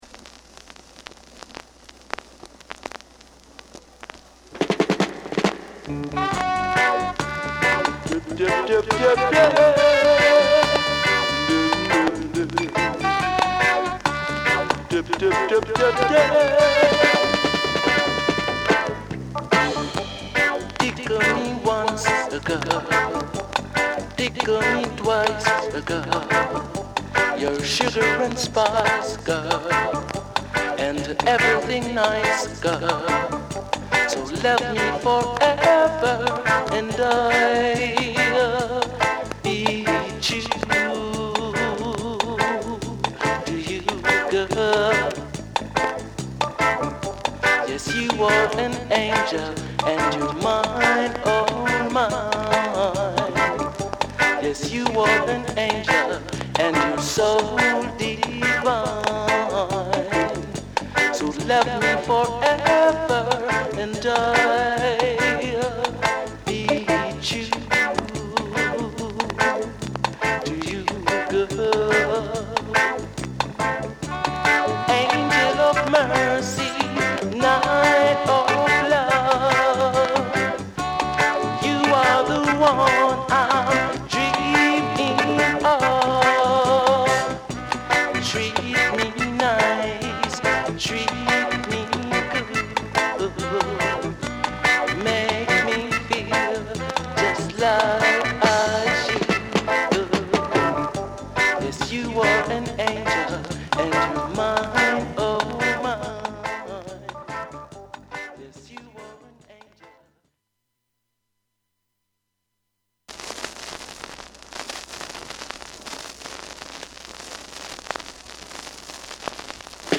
Genre: Reggae / Roots